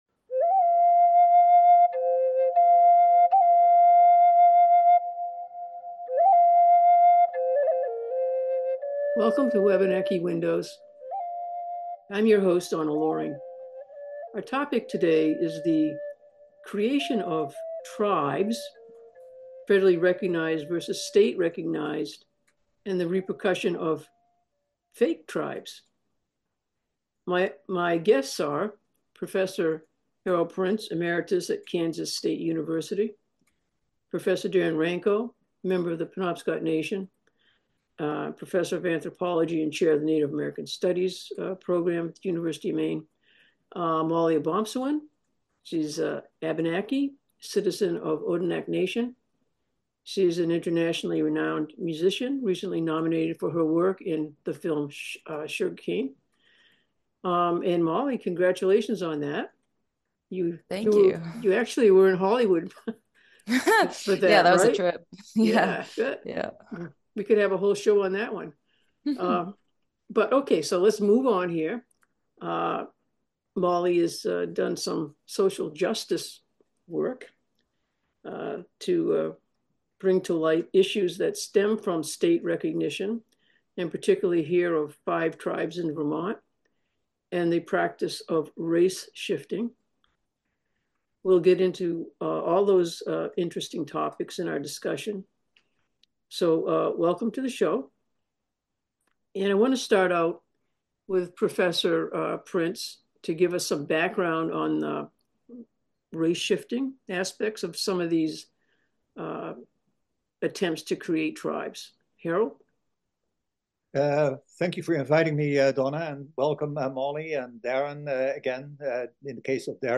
Blood Quantum Interview
Producer/Host: Donna Loring Other credits